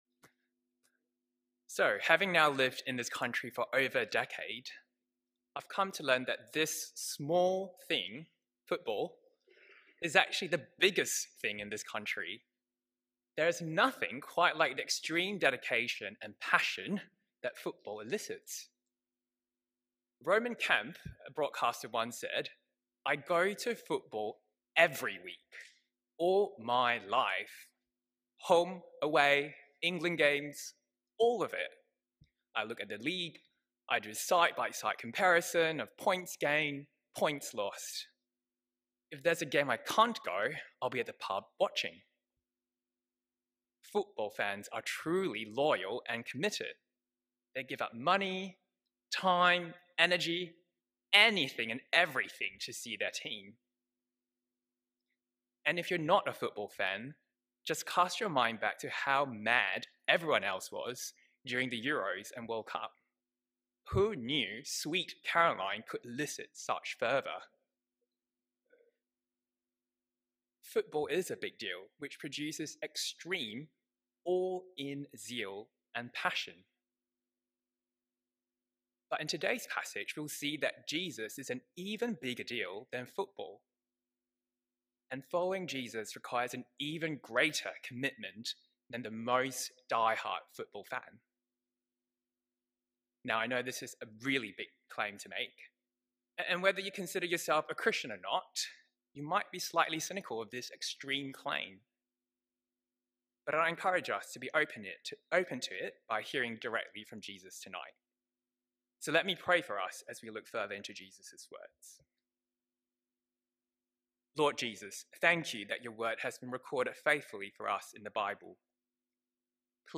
Luke 9:18-26 – PM Service – 2nd November 2025